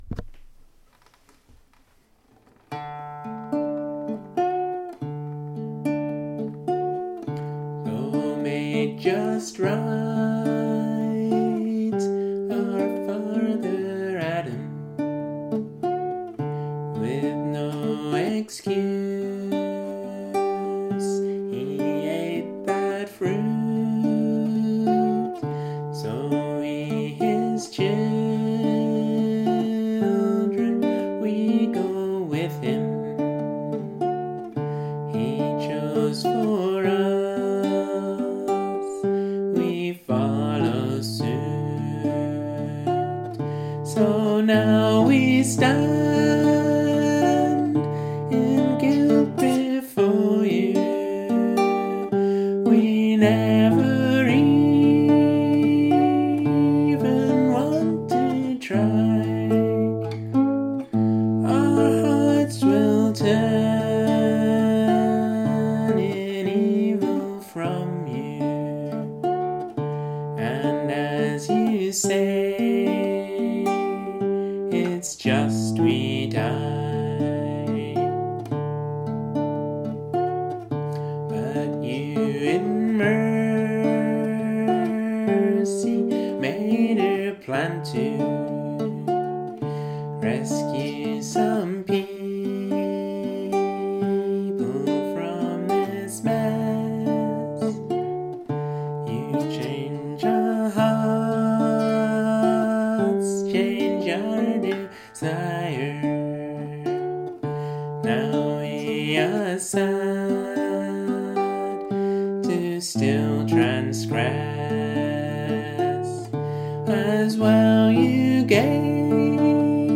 This song is written to a slightly altered version of the folk tune “Poor Wayfaring Stranger”.
Tune with words (Gtr.): MuseScore PDF Tune with words (Gtr. written low): MuseScore PDF Guitar backing: MuseScore MusicXML PDF Audio: me & my guitar 1